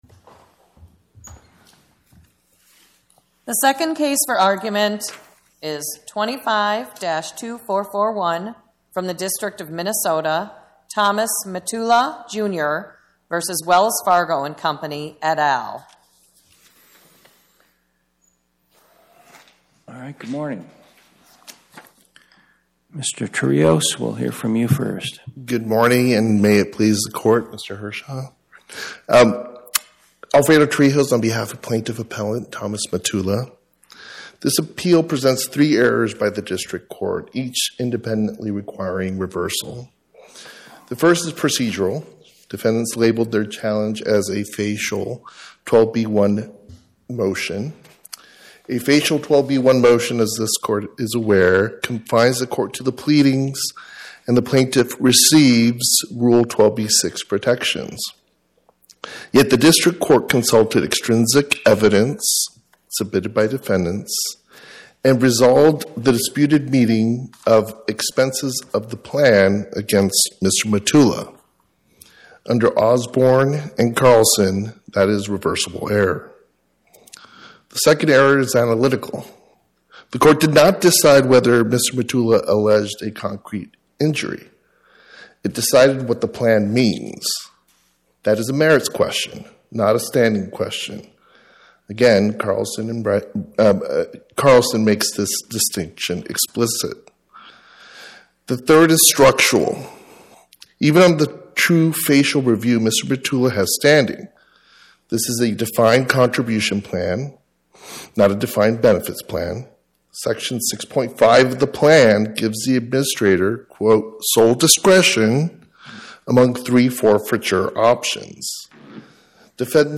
Oral argument argued before the Eighth Circuit U.S. Court of Appeals on or about 03/18/2026